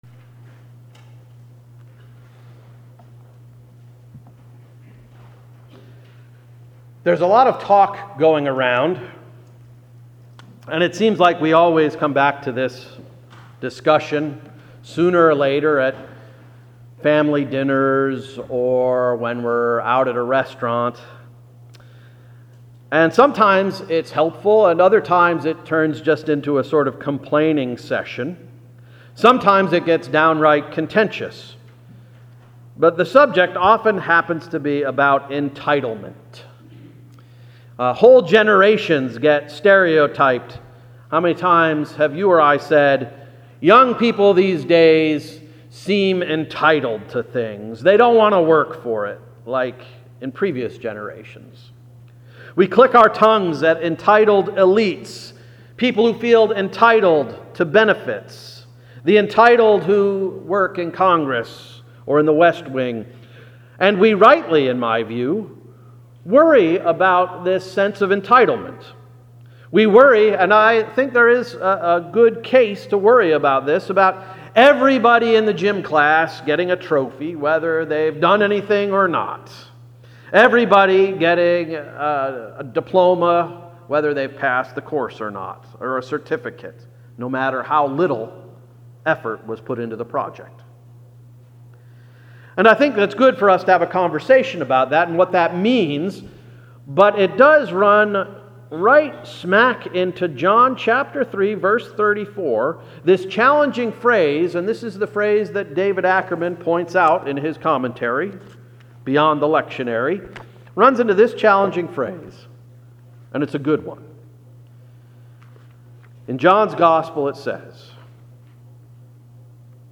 September 24, 2017 Sermon — “That’s not your trophy!”